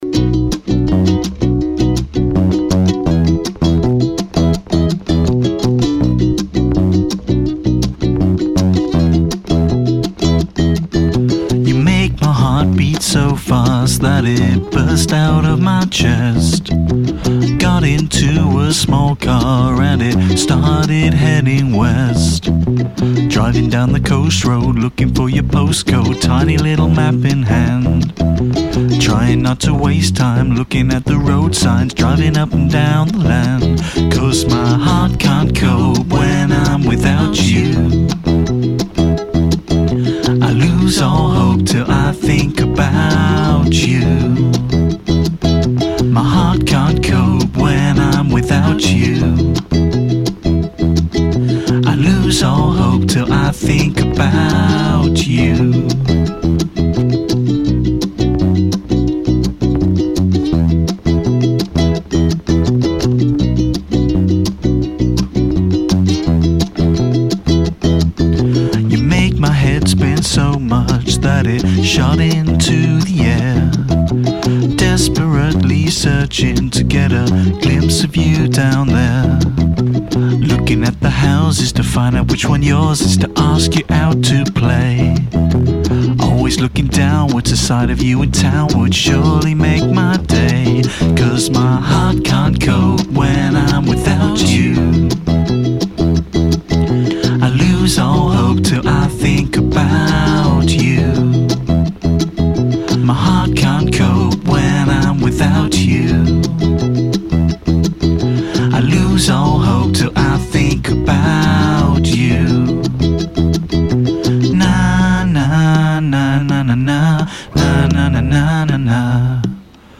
Heart Can't Cope (Ukulele Version)